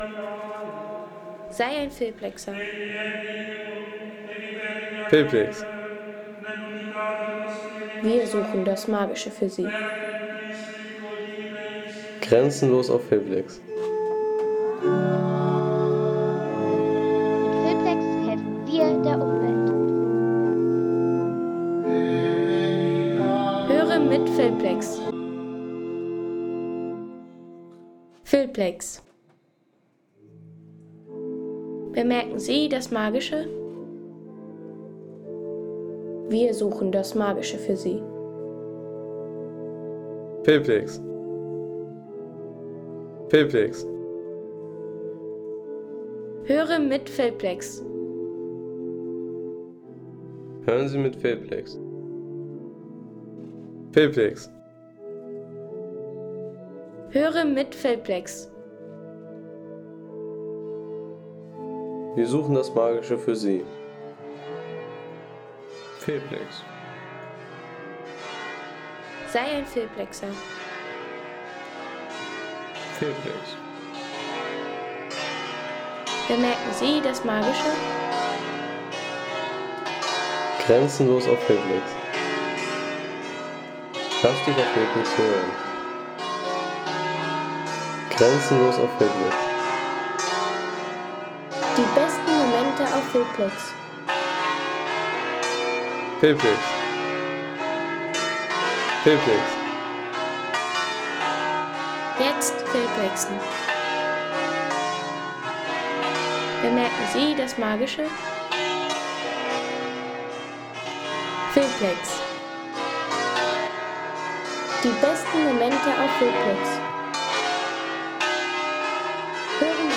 Gottesdienst in der Kollegiatkirche „Basilica di San Vittore“.